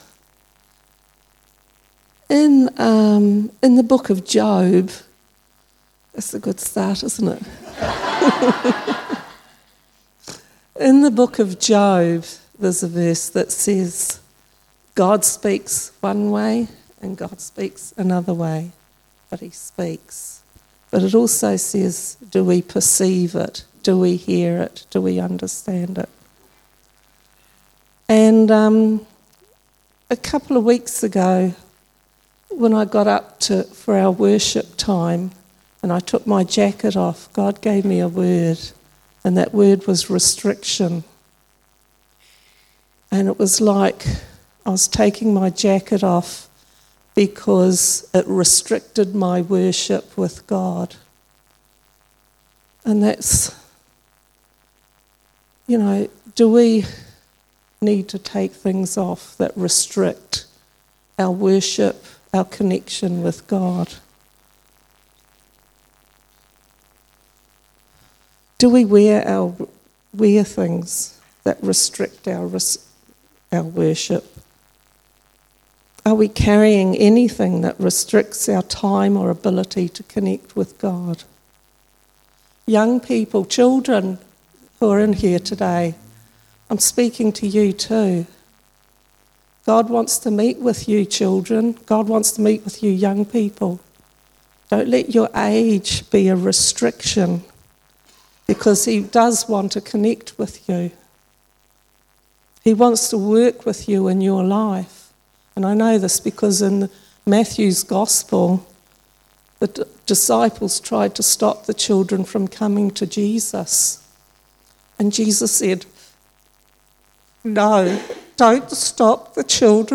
Fathers Day Interviews.